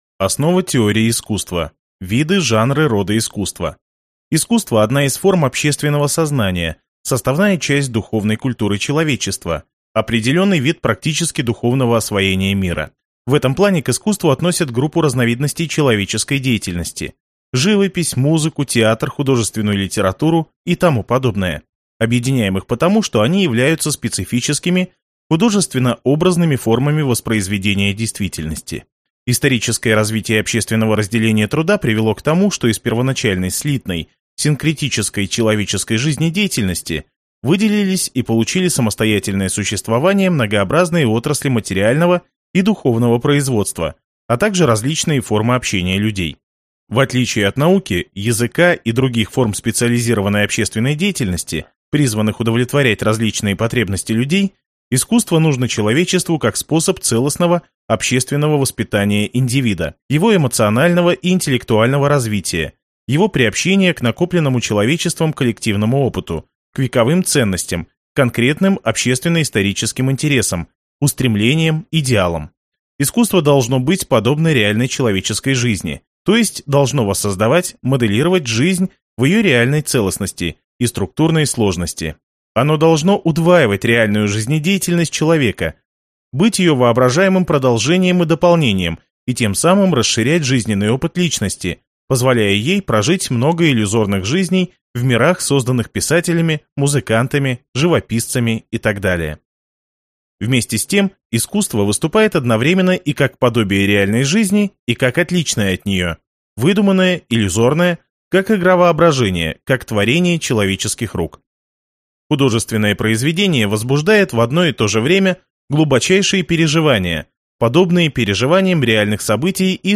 Аудиокнига Лекции по истории искусств | Библиотека аудиокниг